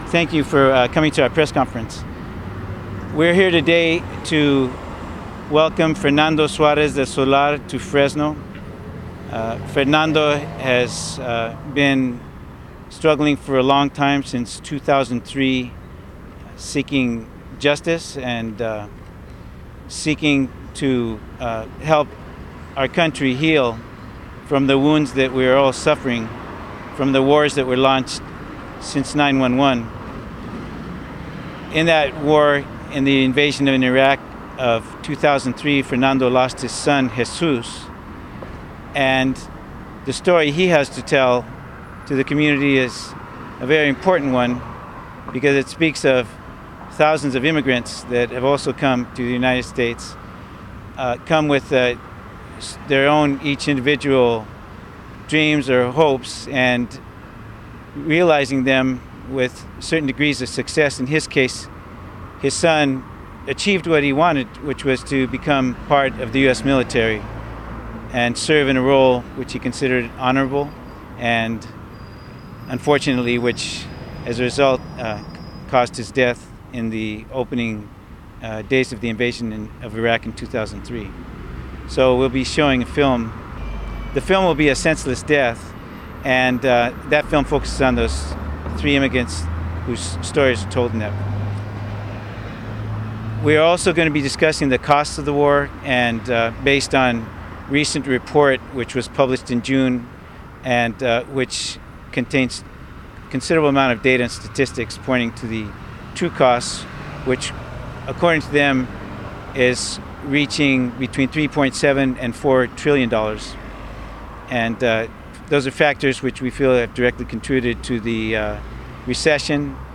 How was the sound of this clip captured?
A press conference was held today (Thursday, August 25) at the Federal Building in Fresno to draw attention to the cost of the Iraq and Afghanistan wars and to announce the March For Peace, which will be held on Saturday, August 27.